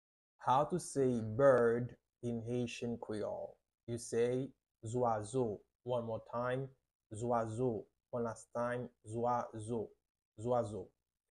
How to say “Bird” in Haitian Creole – “Zwazo” pronunciation by a native Haitian Teacher
“Zwazo” Pronunciation in Haitian Creole by a native Haitian can be heard in the audio here or in the video below: